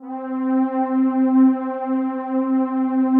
PAD 47-4.wav